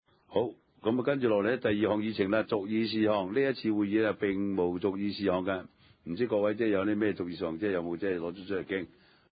地區設施管理委員會第二十次會議
荃灣民政事務處會議廳